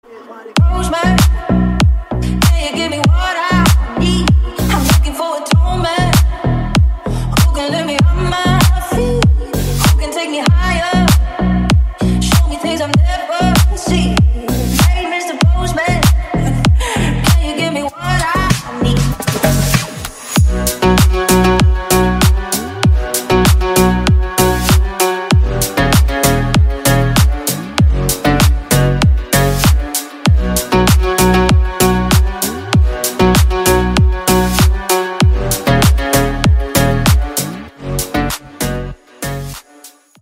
Танцевальные рингтоны
Клубные рингтоны